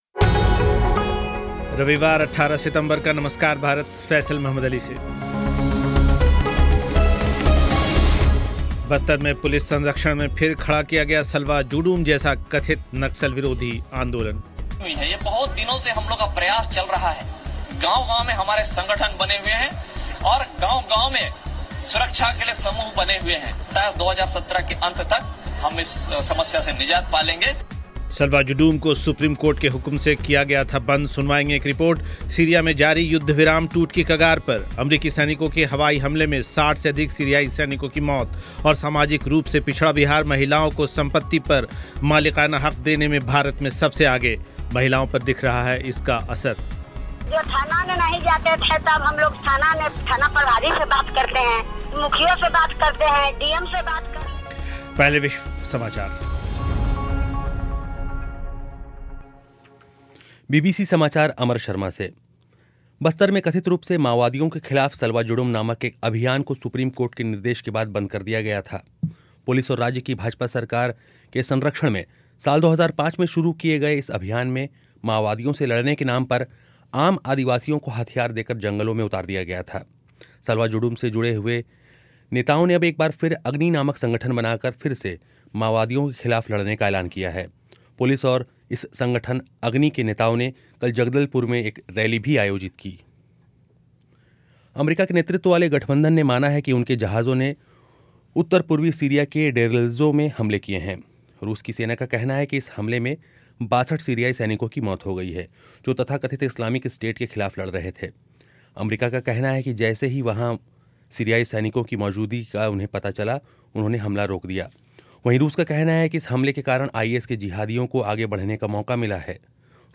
एक साक्षात्कार